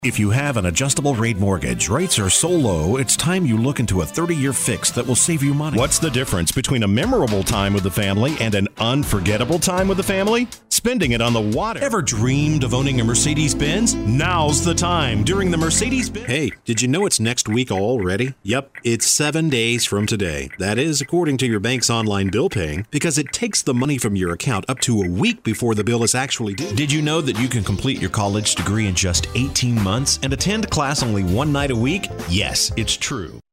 Baritone – Swap A Spot